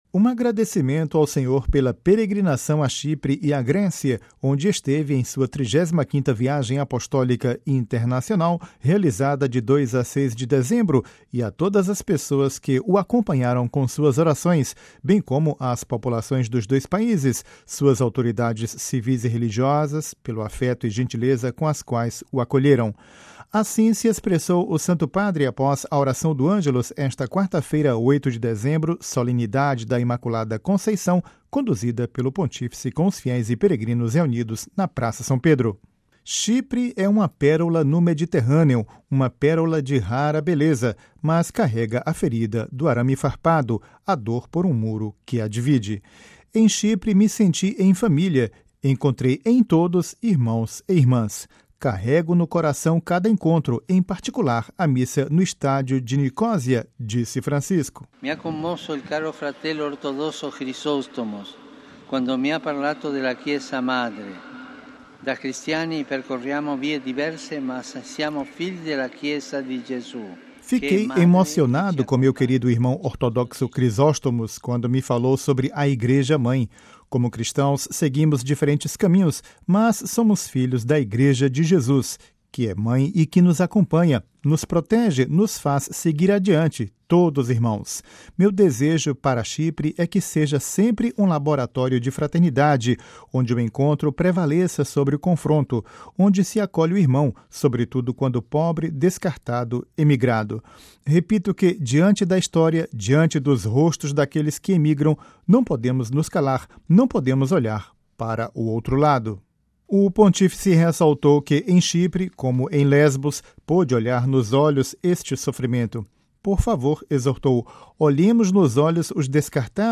Ouça a reportagem com a voz do Papa Francisco